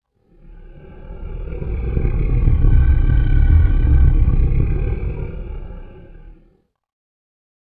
creature-sound